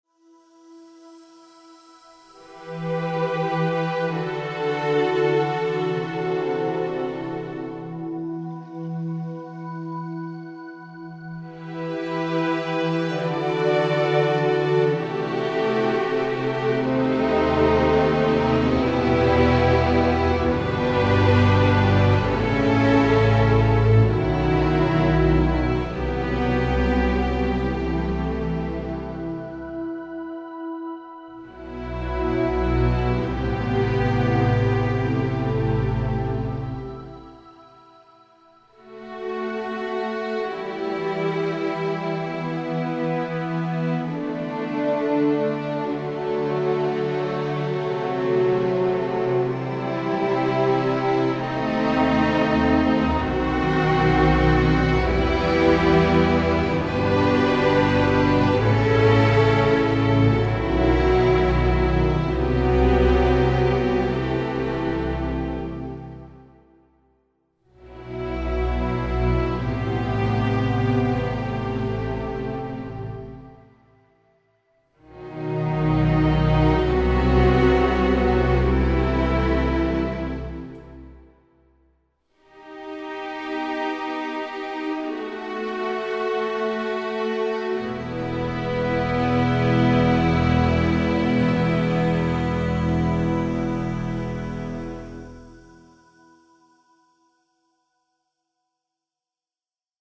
for Strings & Piano